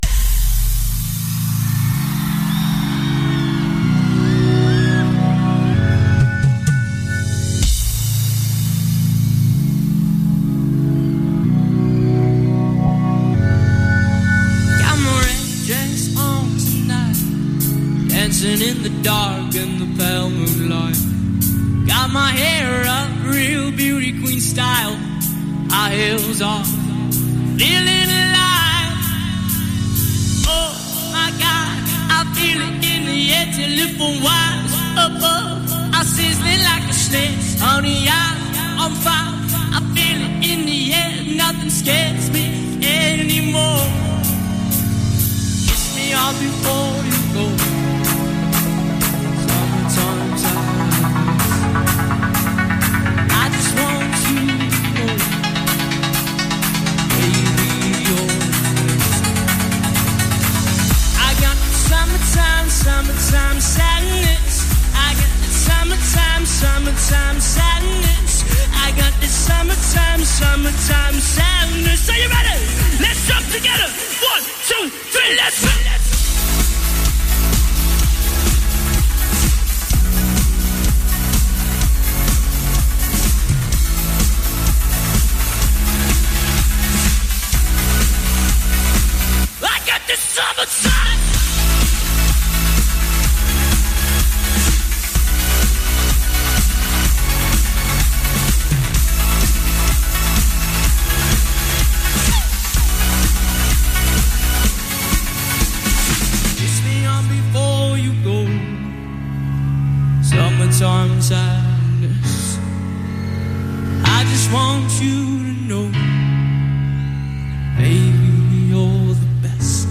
Cover
performed at their last concert of the year in New Mexico